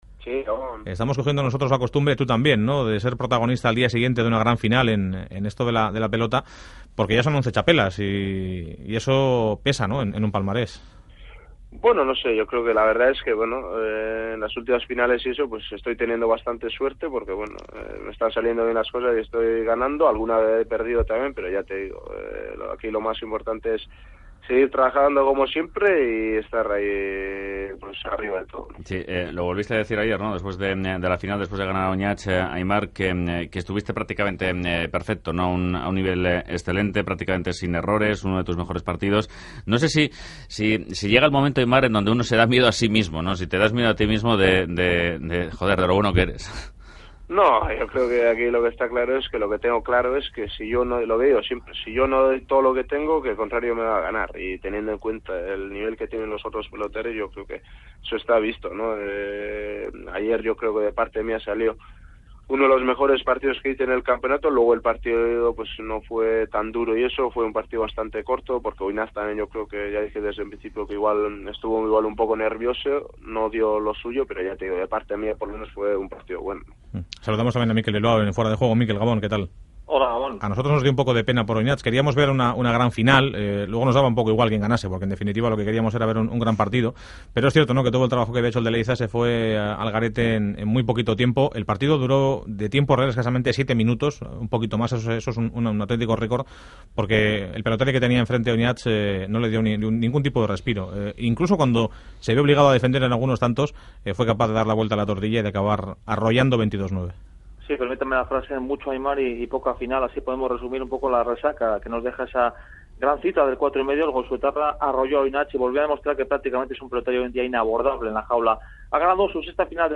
Audio entrevista al pelotari Aimar Olaizola | Fuera de juego